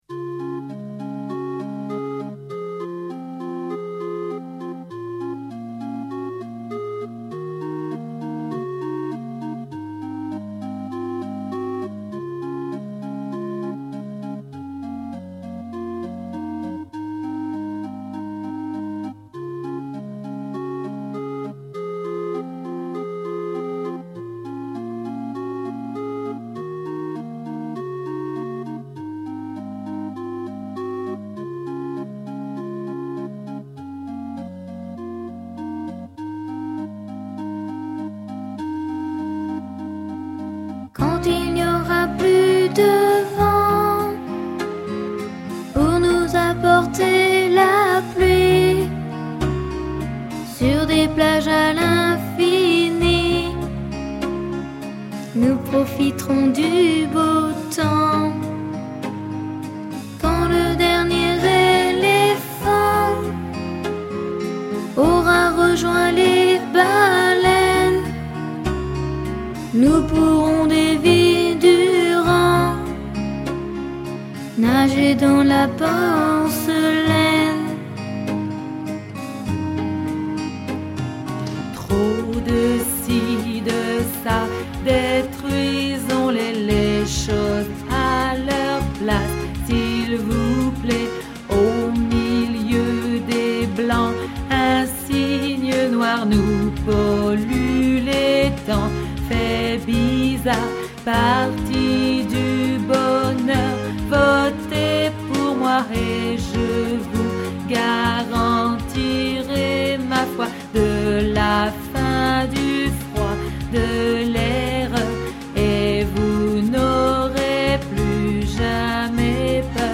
Chanson pour enfants